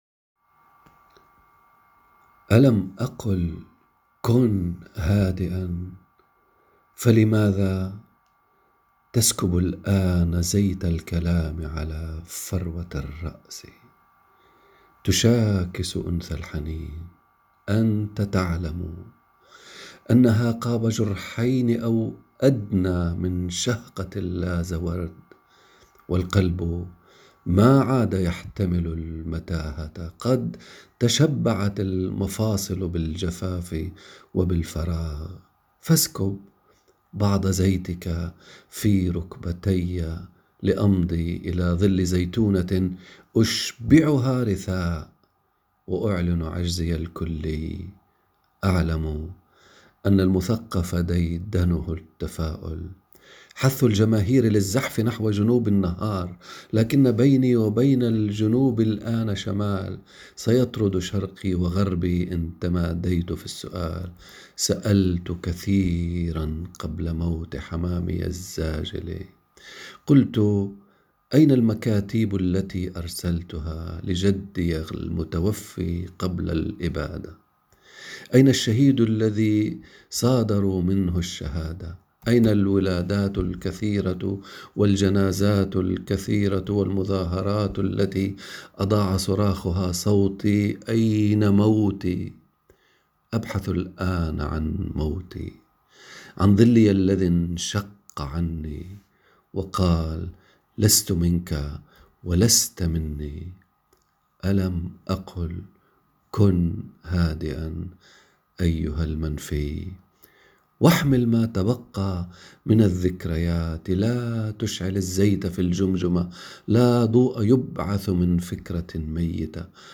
صوتٌ يتسلل كموسيقى عذبة من أوتار الكمان ويدخل الى القلوب !